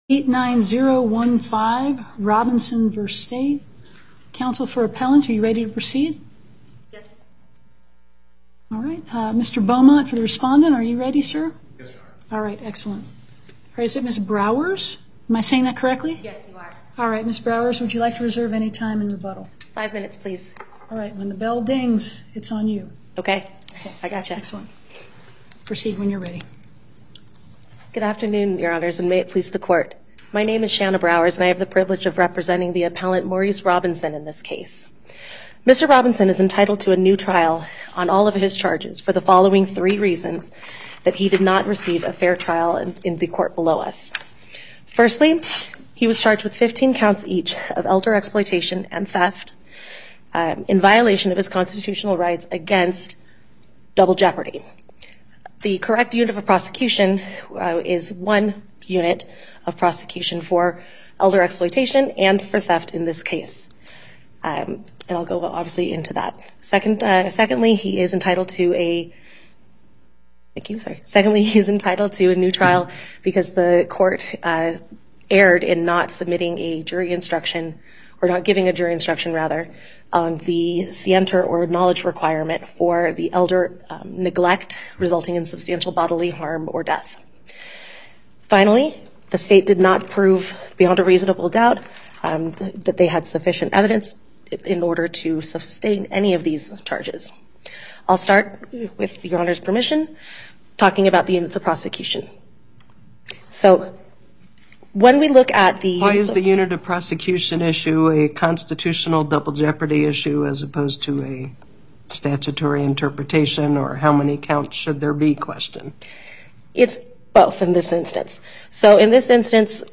Justice Stiglich presiding
as counsel for Appellant
as counsel for Respondent